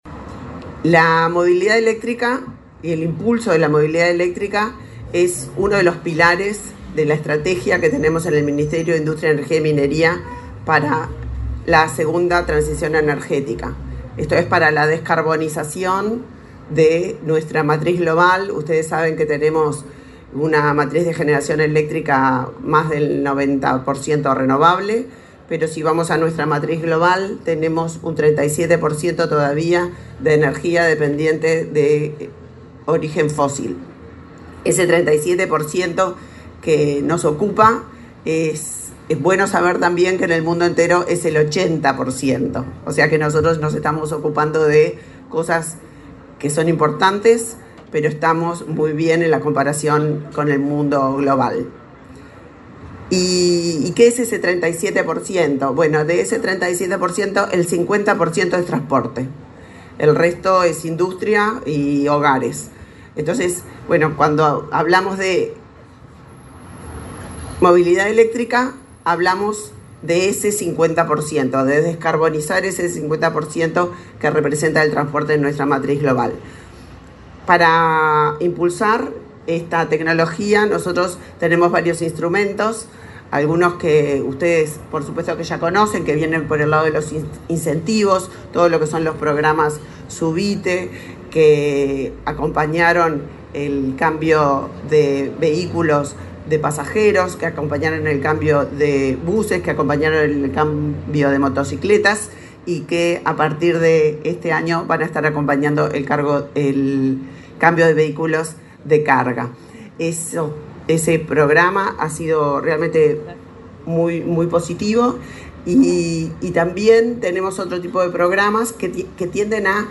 Palabras de la ministra de Industria, Elisa Facio
Este viernes 27 en Montevideo, la ministra de Industria, Elisa Facio, encabezó el acto de apertura de la Expo Movilidad Eléctrica.